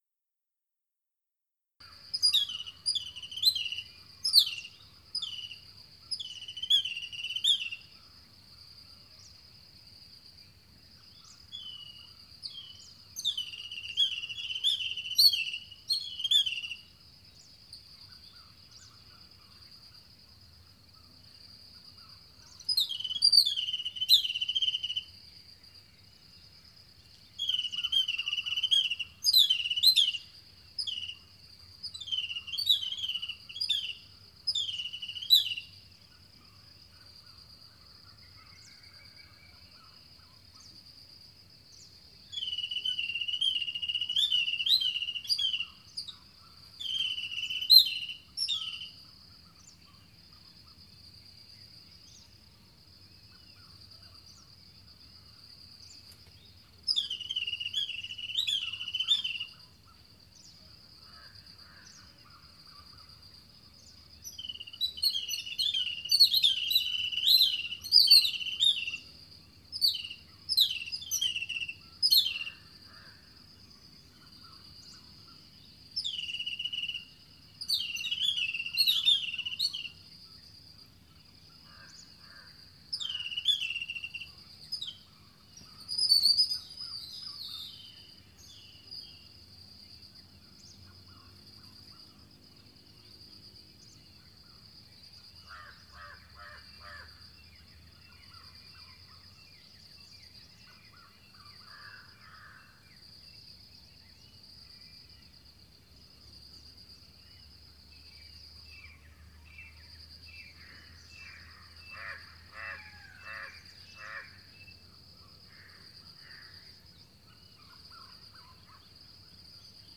Les premiers oiseaux saluaient le soleil, lequel pointait timidement le bout de son nez, sans trop se presser.
Bref, un réveil à des milliers de kilomètres de la mère patrie (pour ne rien vous cacher je suis en Inde), entourée du pépiement joyeux de piafs exotiques.
Mais, sur mon rocher, j’ai aussi pensé à vous et enregistré une petite ambiance sonore, pour partager :
Léopards et éléphants ont cédé la place aux oiseaux du petit jour.
Sons-jungle-matin.mp3